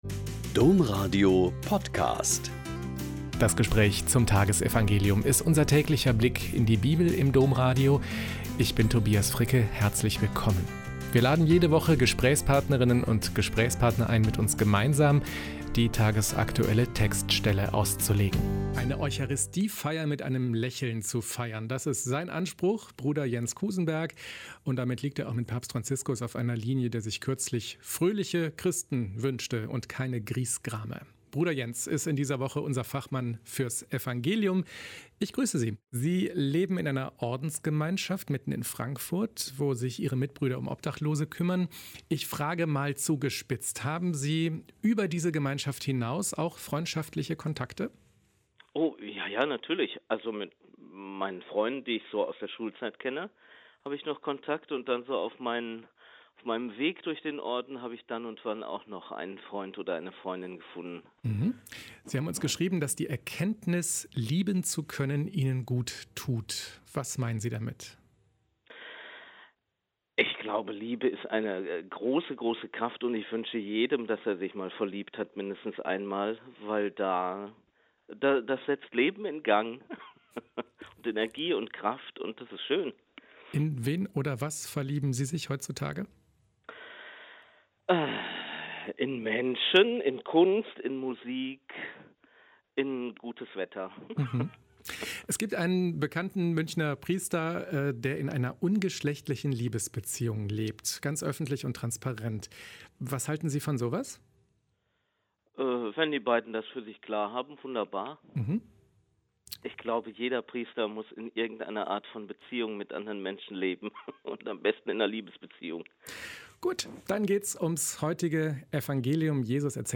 Lk 11,5-13 - Gespräch